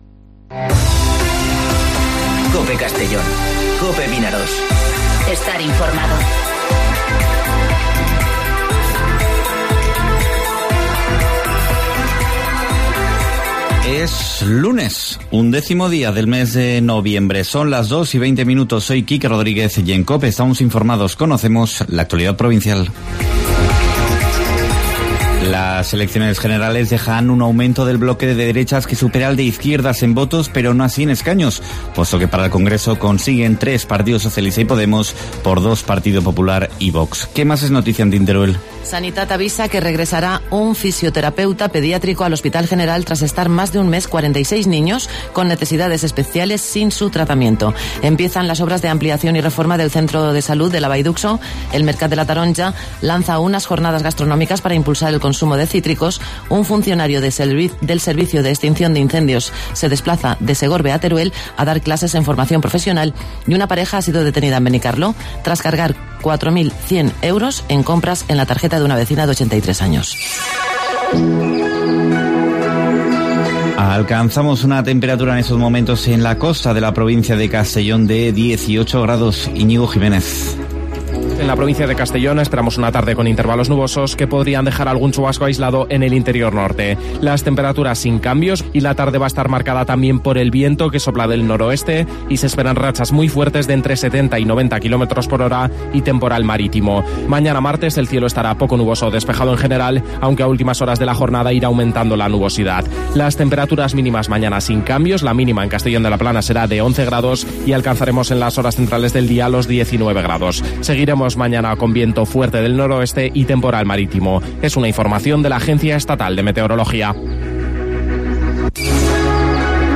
Informativo Mediodía COPE en Castellón (11/11/2019)